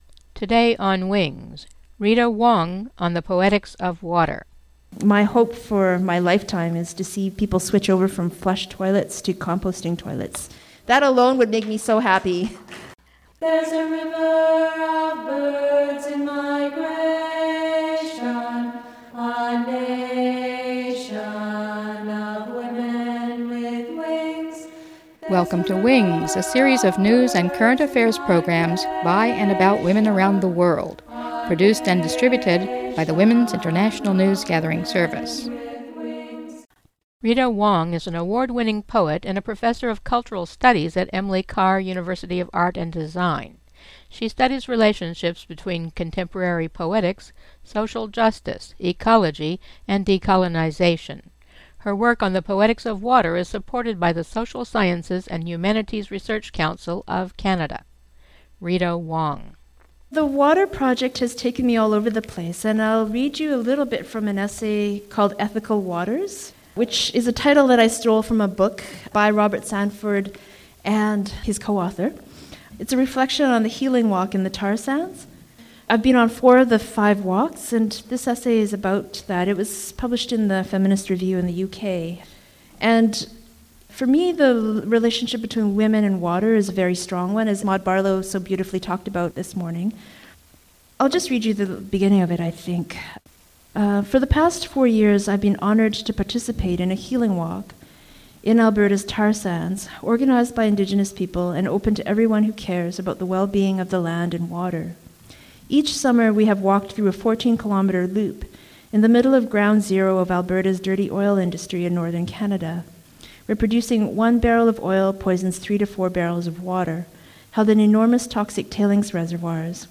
A reading